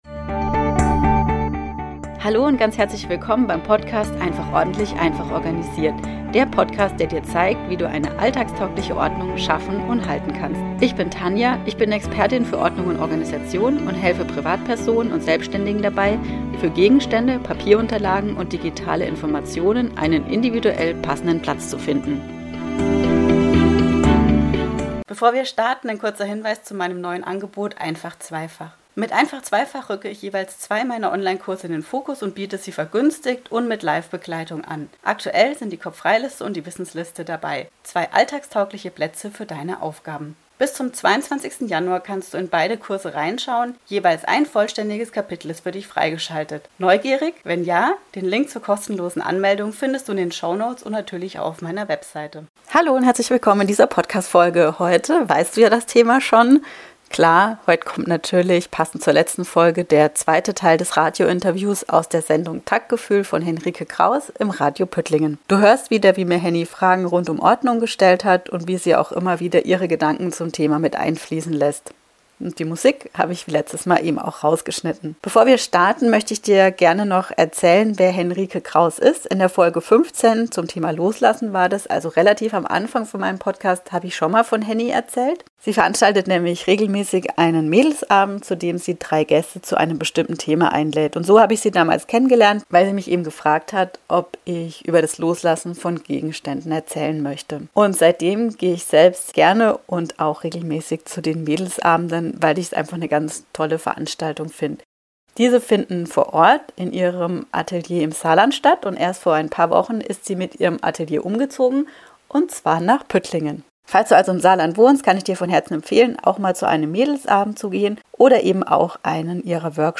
Radiointerview Teil 2 (Folge 62) ~ einfach ordentlich - einfach organisiert Podcast
Als Gast in der Sendung Taktgefühl bei Radio Püttlingen